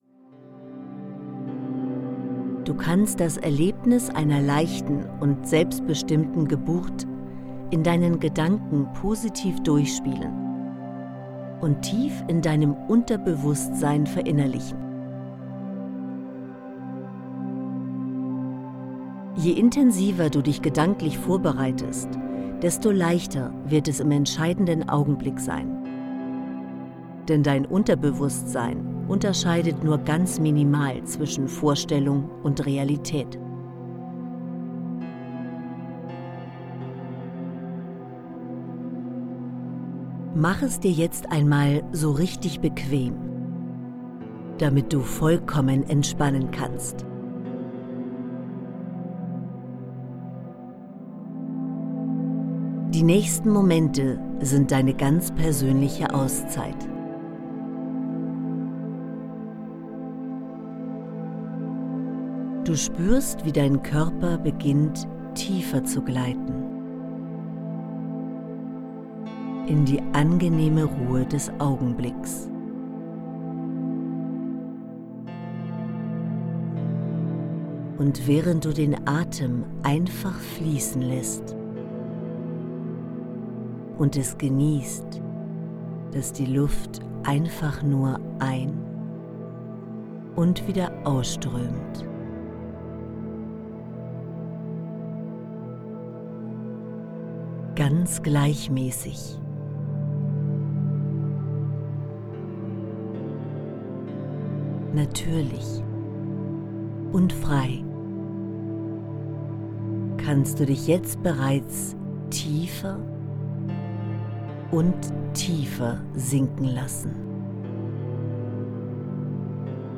Dieses Mindstyle Audiobook ist ein harmonisches Zusammenspiel von inspirierenden Texten, bewegenden Stimmen und sanfter Begleitmusik.